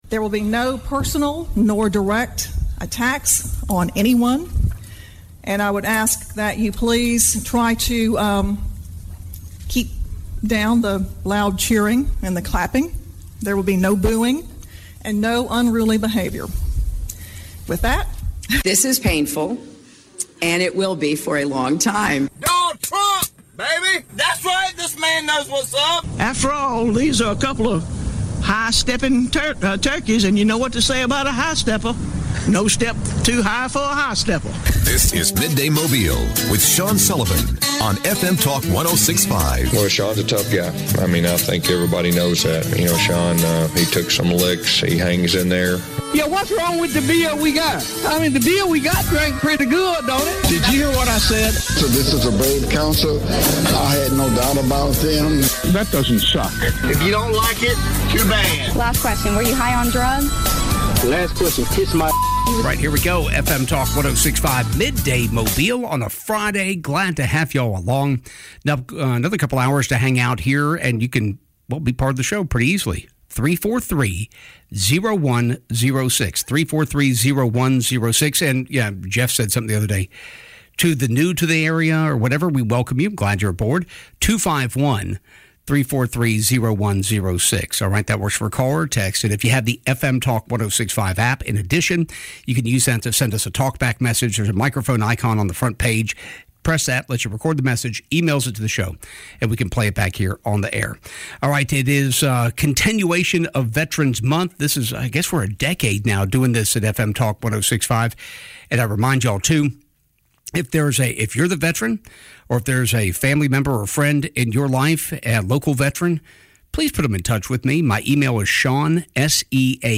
Midday Mobile - Veteran's Month interview